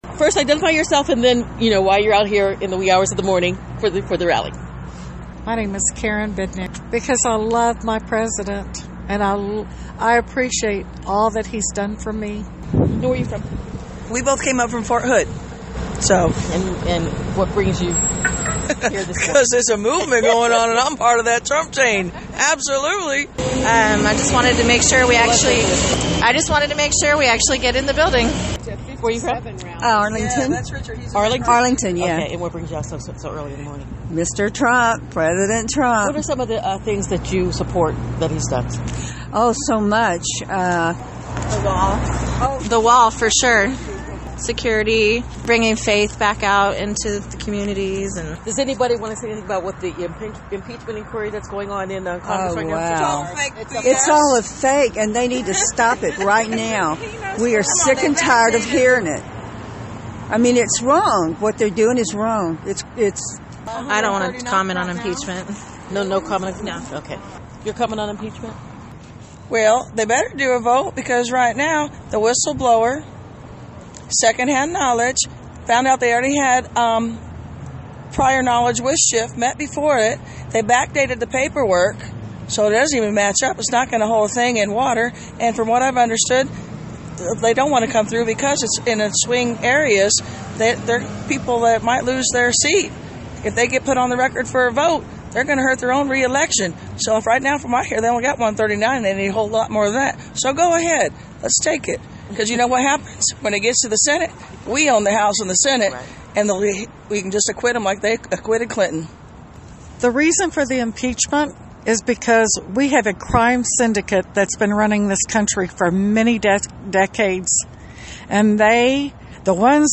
Dallas (WBAP/KLIF) – The crowd is already gathering outside the American Airlines Center for the Trump campaign rally tomorrow evening.
Among those in the small crowd that is slowly building, this group of women talked to us about why they came out so early.